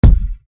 DHL KICK 1.mp3